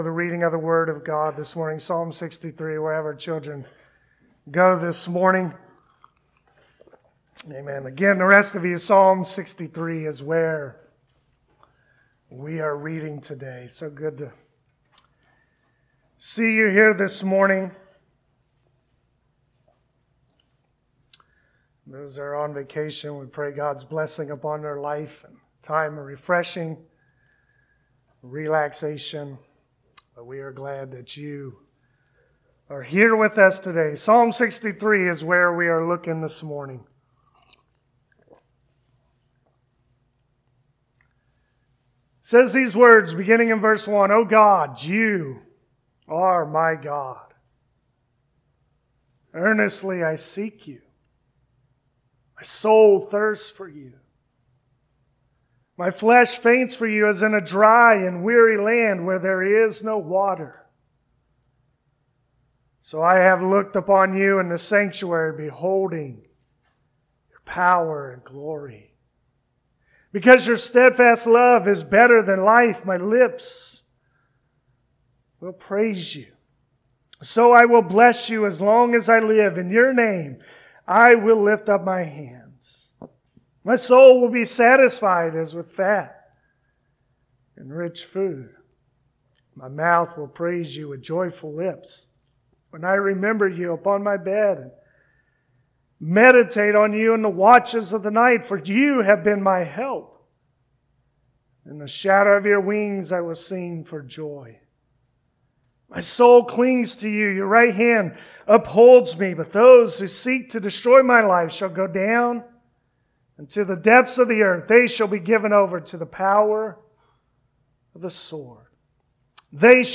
Passage: Psalm 63 Service Type: Sunday Morning We can only find true satisfaction when we find it in God alone.